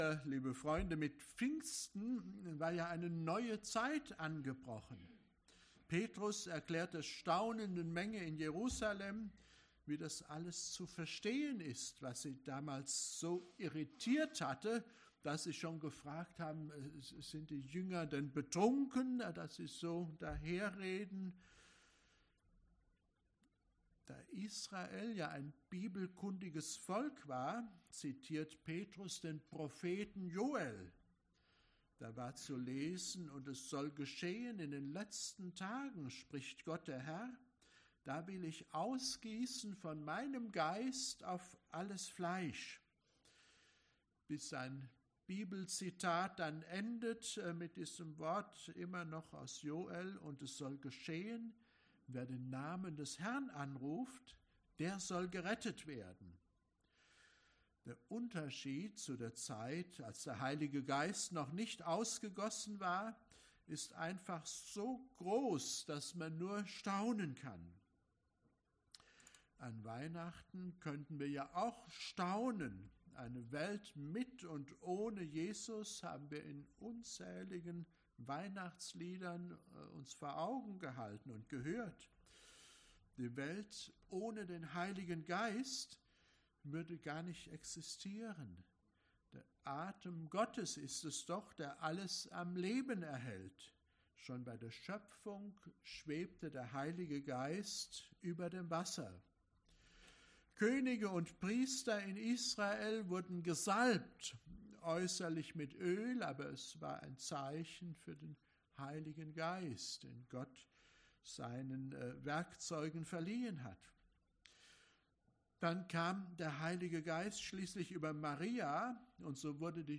Evangelisch-Freikirchliche Gemeinde Borken - Predigten anhören